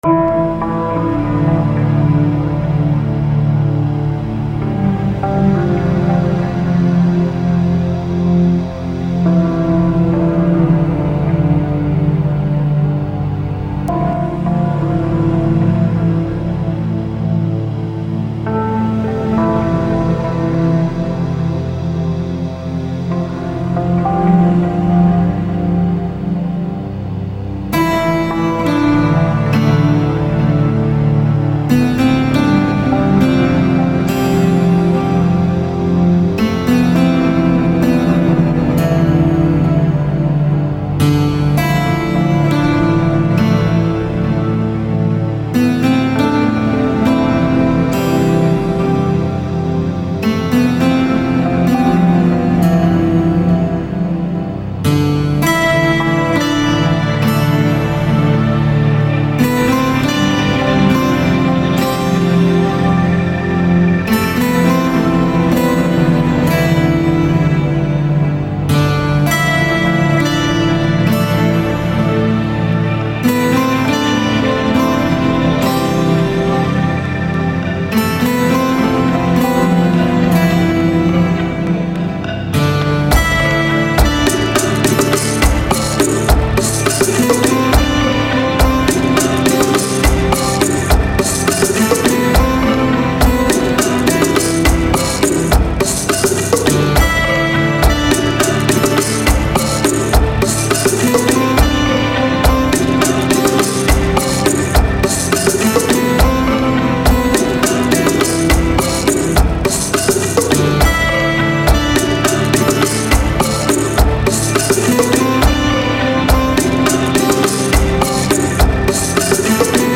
Looking For An Old Love - Omnisphere+Kontakt Testing
Ive been trying to find many vstis to get the best production of chillout/newage genres until I noticed I could mix Omnisphere + Kotankt :D Check wot we got!!!